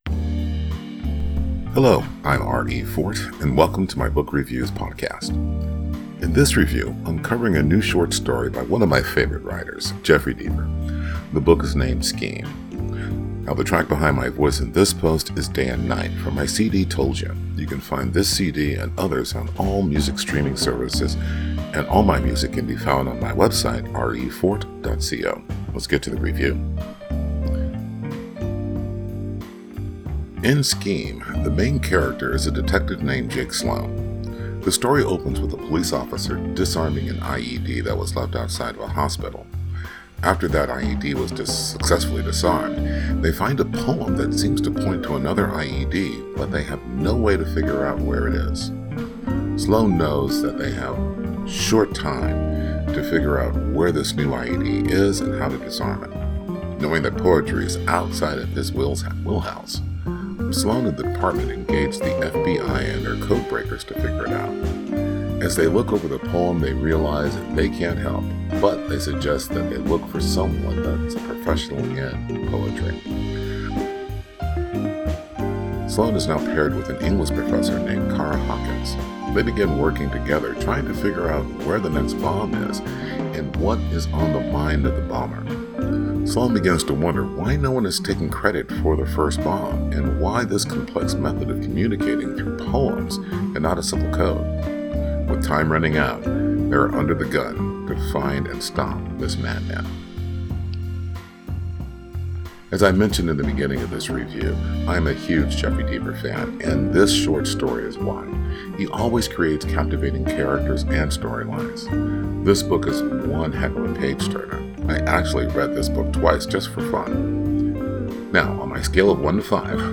Podcast-Book-Review-Scheme.wav